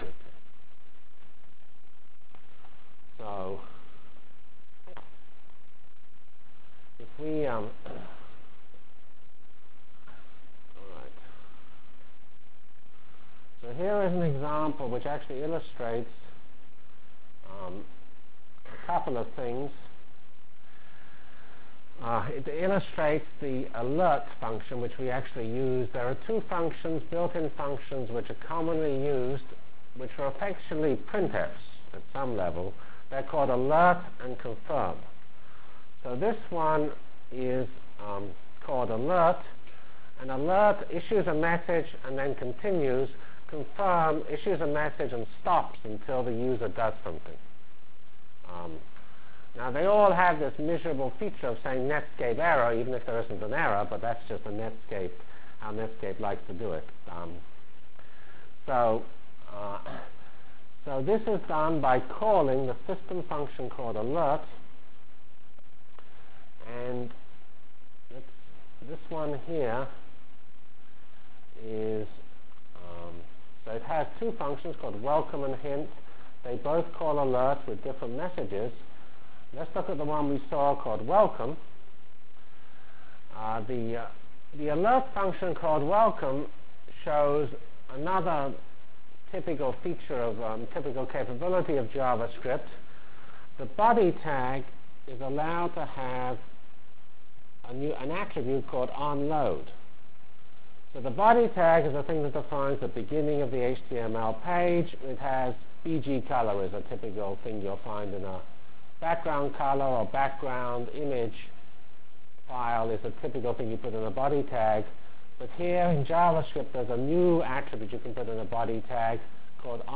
Full HTML for GLOBAL Feb 12 Delivered Lecture for Course CPS616 -- Basic JavaScript Functionalities and Examples